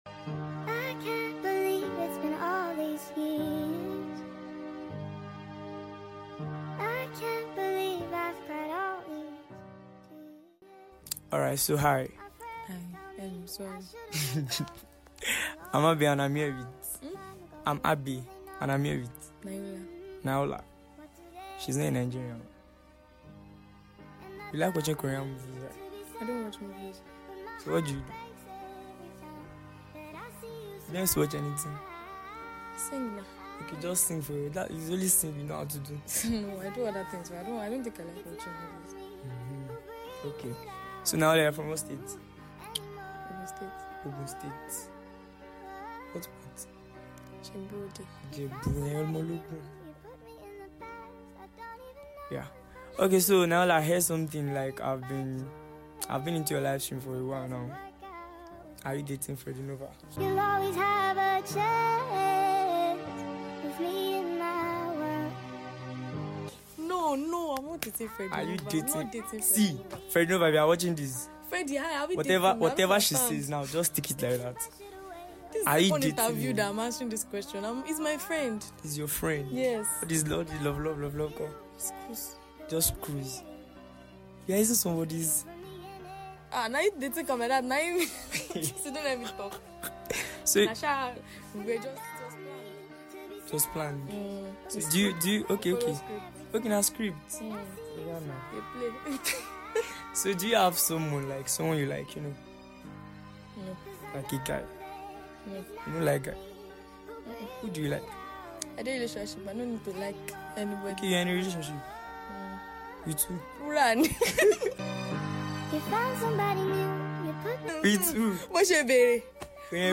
interview
Her voice be sweet